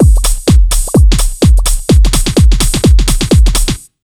127BEAT6 2-R.wav